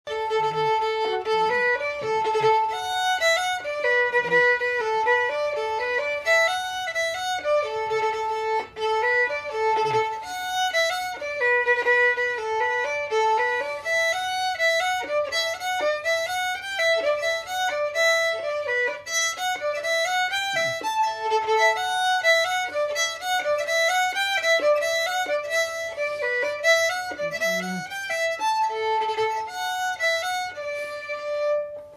Key: D
Form: Reel
M: 4/4
Region: Scotland